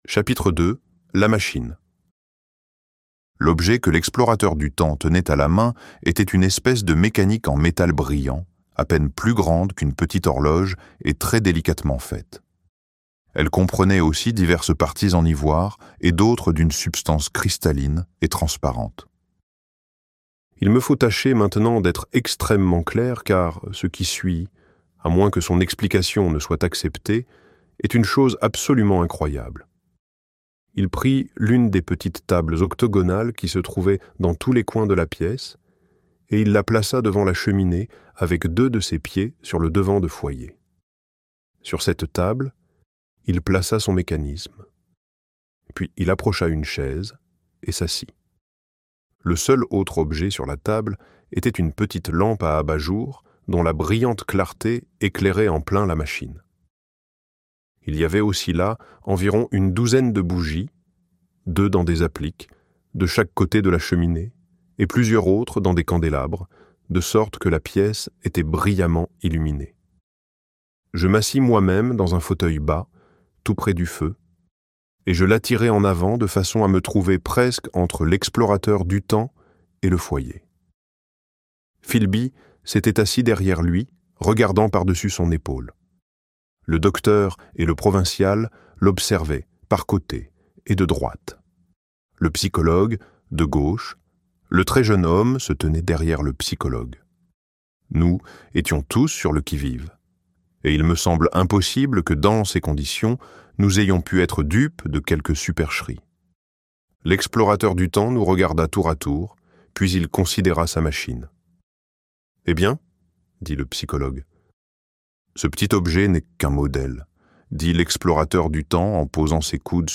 La Machine à explorer le temps - Livre Audio